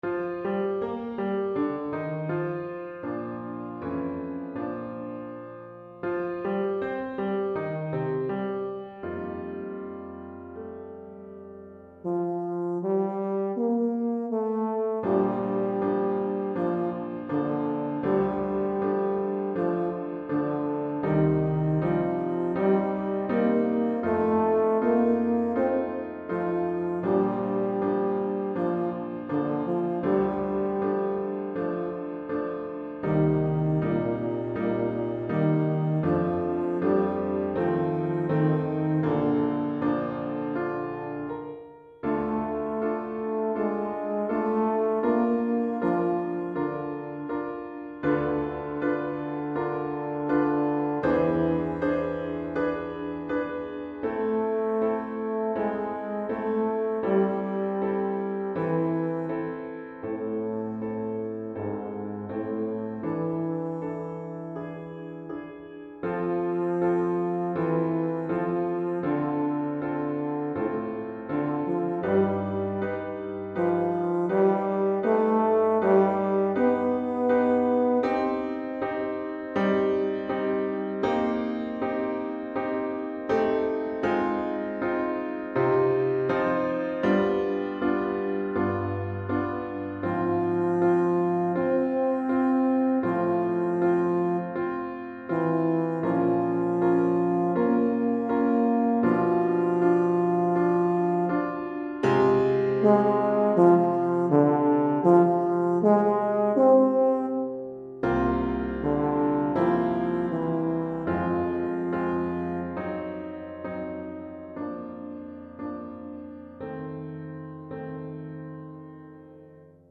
Euphonium Ensemble